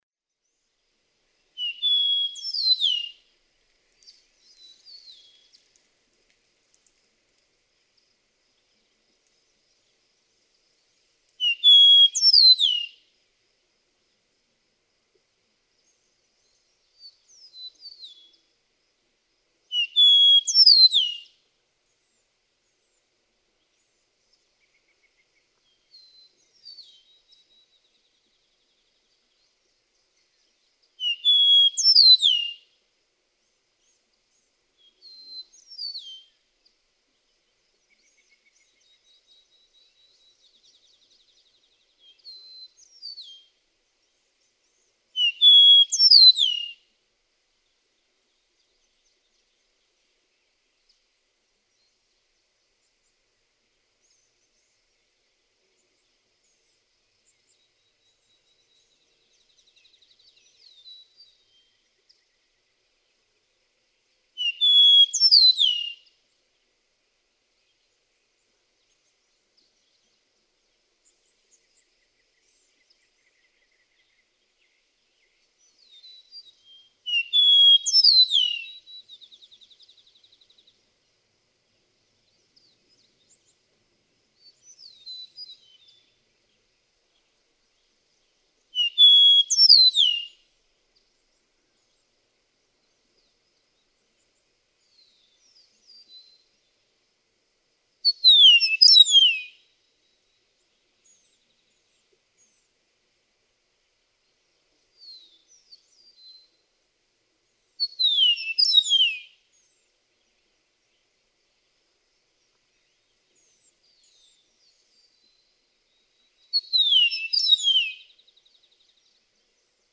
Eastern meadowlark: The learned eastern songs are a sliding spring-o'-the-yeeaar.
Natural Bridge, Virginia.
617_Eastern_Meadowlark.mp3